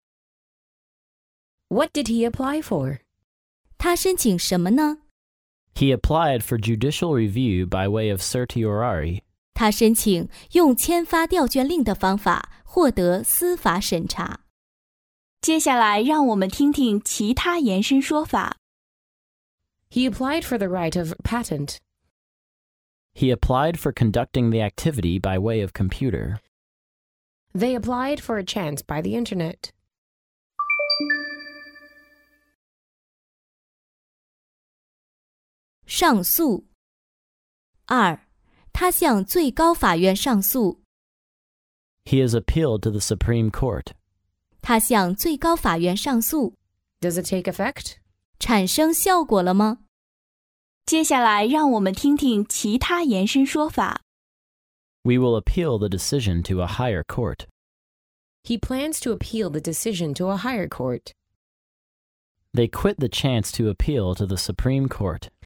在线英语听力室法律英语就该这么说 第66期:他申请用签发调卷令的方法获得司法审查的听力文件下载,《法律英语就该这么说》栏目收录各种特定情境中的常用法律英语。真人发音的朗读版帮助网友熟读熟记，在工作中举一反三，游刃有余。